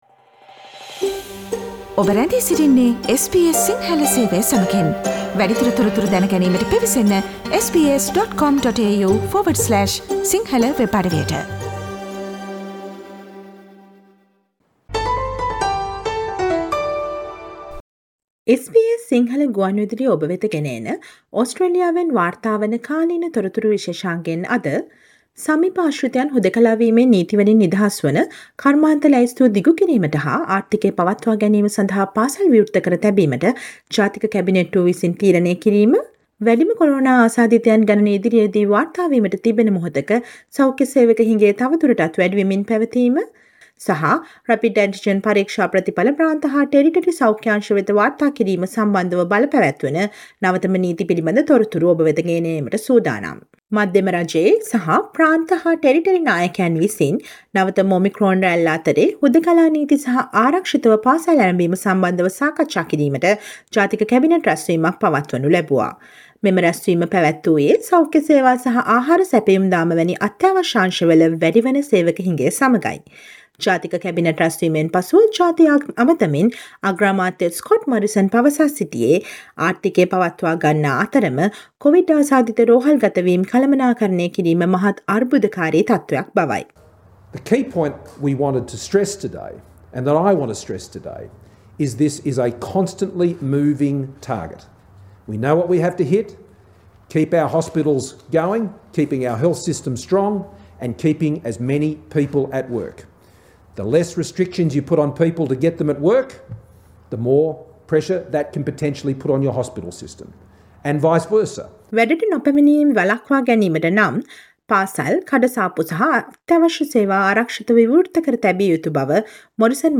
කැබිනට් තීරණ මත වෙනස් වූ හුදෙකලා නීති, අත්‍යාවශ්‍ය සේවා වල වැඩි වන සේවක හිඟය සහ ඕස්ට්‍රේලියාව පුරා නවතව තොරතුරු රැගත් ජනවාරි 14 වෙනි සිකුරාදා ප්‍රචාරය වූ SBS සිංහල ගුවන්විදුලි සේවයේ කාලීන තොරතුරු ප්‍රචාරයට සවන් දෙන්න